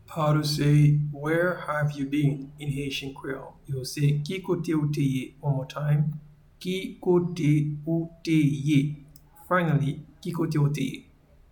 Pronunciation and Transcript:
Where-have-you-been-in-Haitian-Creole-Ki-kote-ou-te-ye.mp3